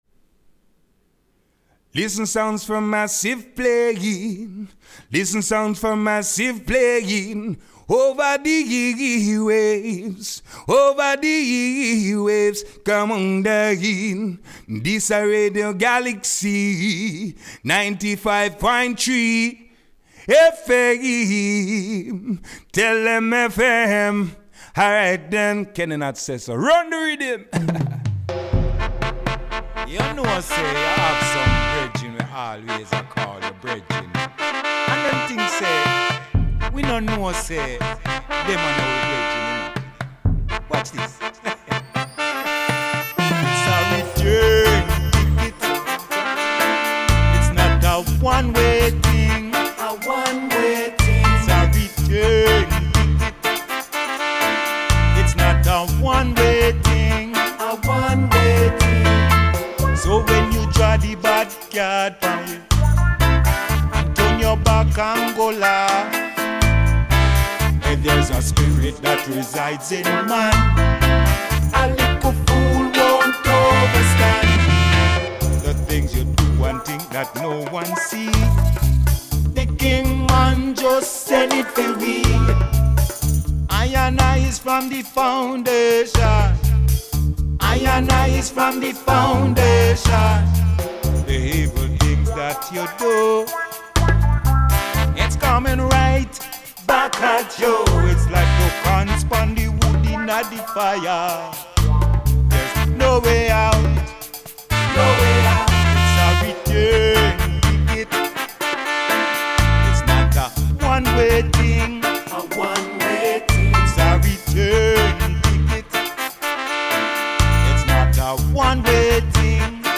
rendez-vous reggaephonique
enregistré hier soir dans les studios